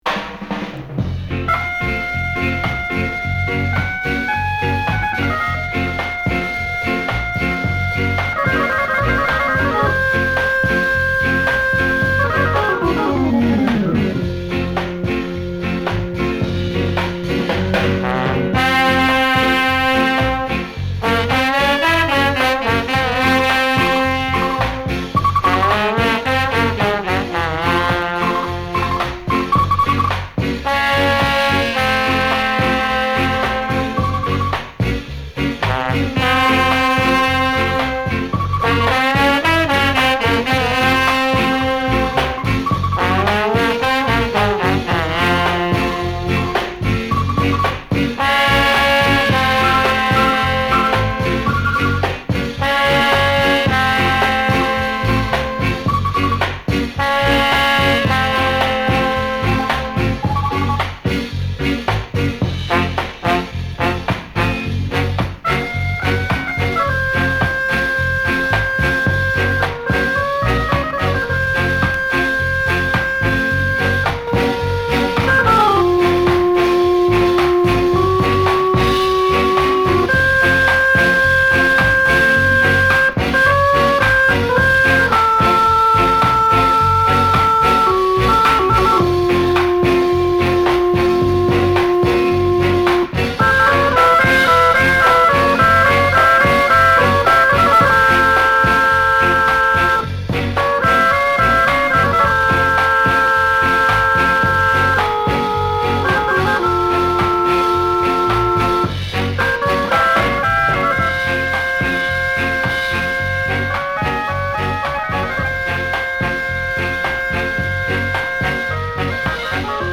Big piece of instrumental tune
Featuring a collection of Jamaica’s finest instrumentalists.